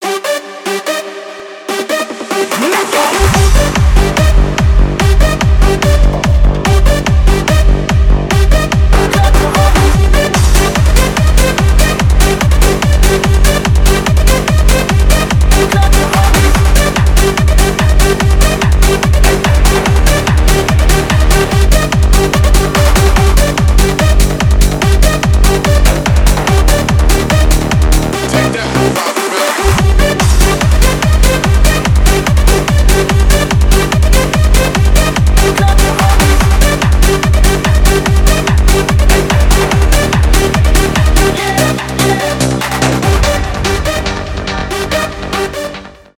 техно
клубные